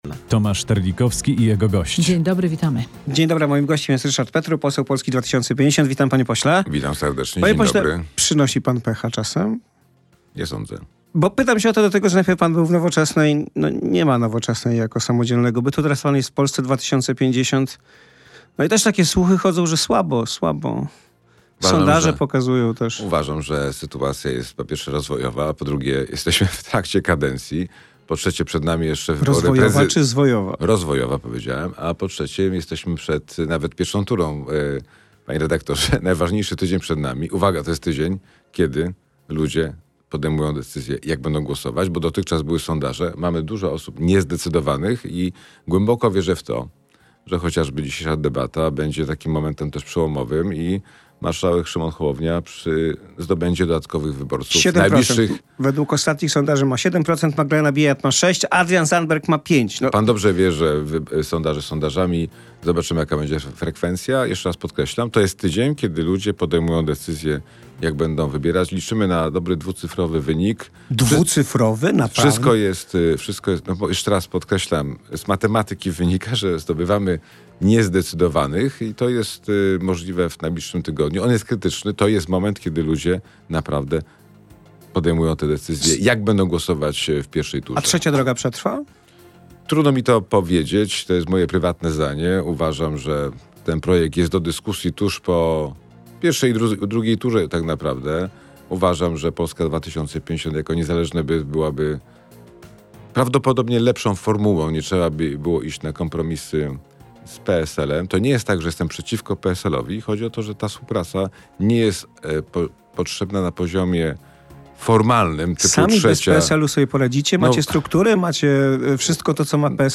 Gośćmi są nie tylko politycy, ale i ludzie ze świata kultury czy sportu.